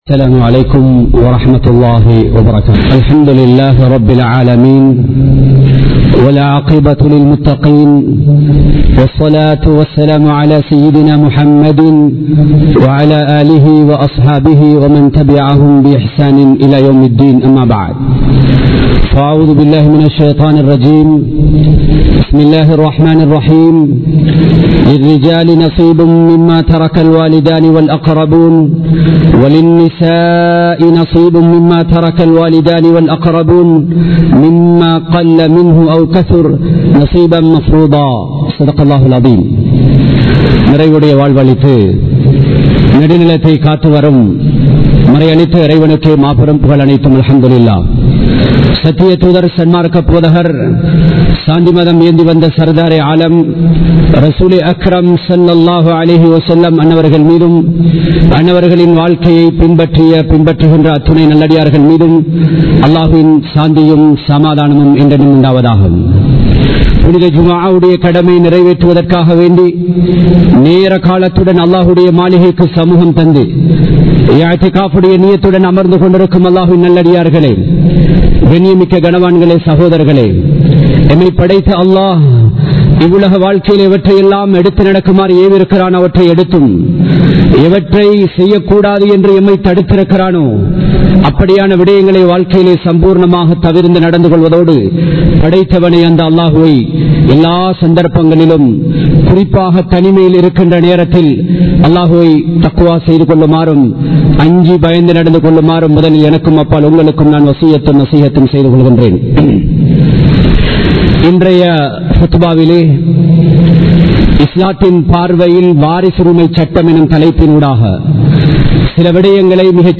வாரிசுரிமைச் சட்டம் | Audio Bayans | All Ceylon Muslim Youth Community | Addalaichenai
Dehiwela, Muhideen (Markaz) Jumua Masjith 2022-12-16 Tamil Download